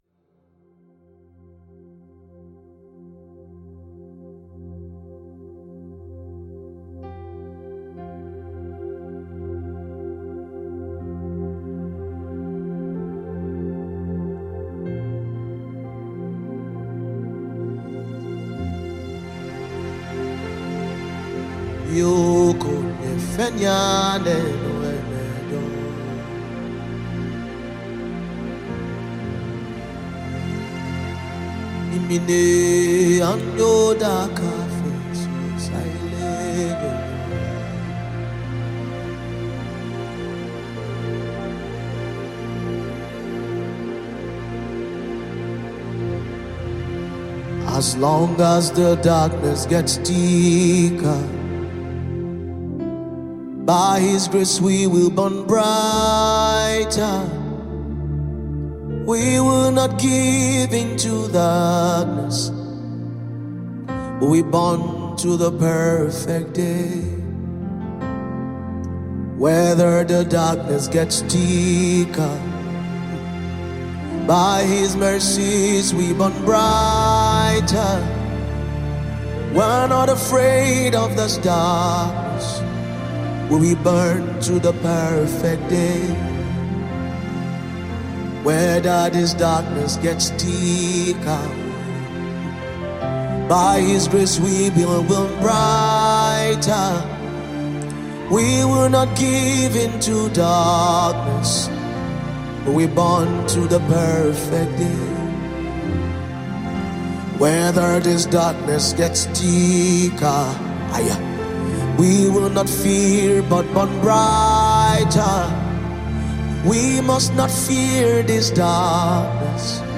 Nigerian gospel